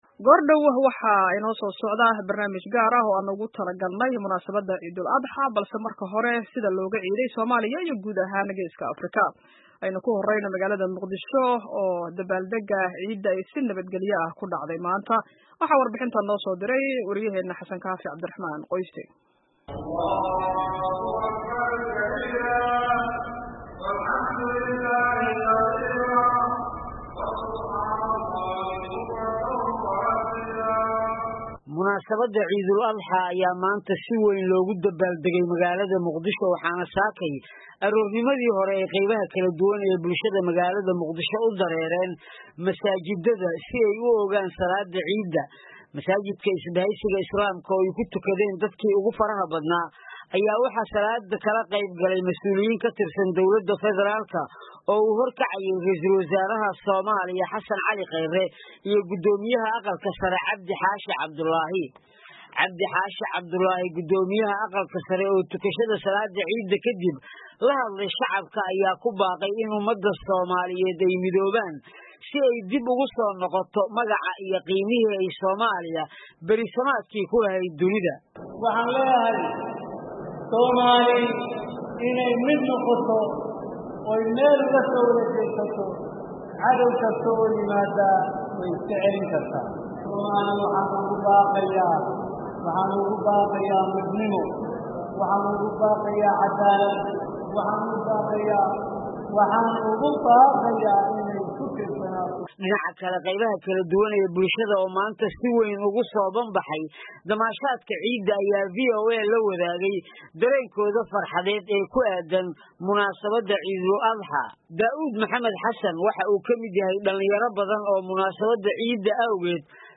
Dhageyso warbixinnada Ciidda